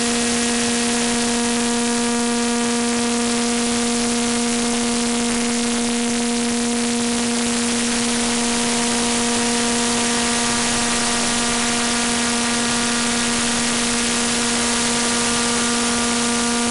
Fmcw_240HZ_2nfm.mp3